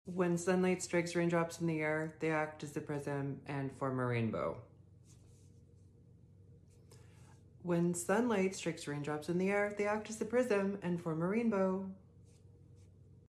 Float your pitch and don’t sink for a melodic feminine voice! When speaking at a higher pitch it’s easy to let your pitch slip and sink at the ends of phrases.